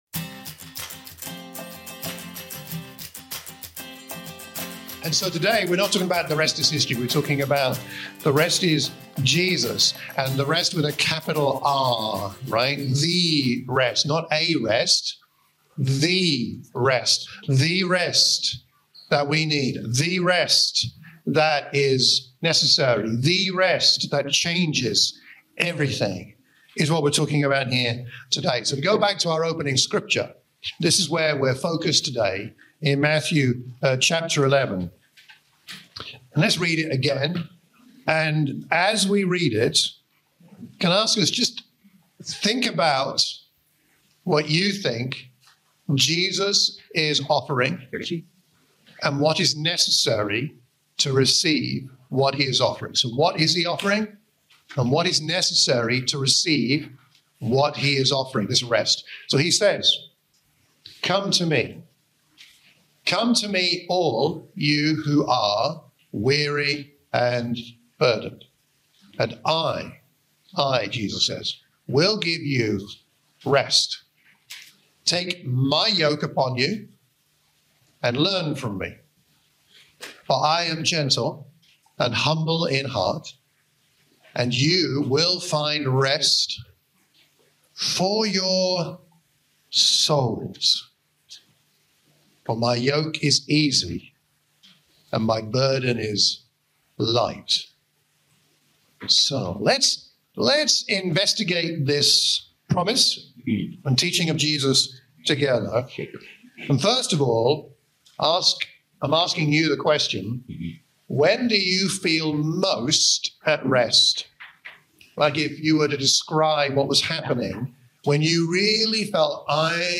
A sermon for the Watford church of Christ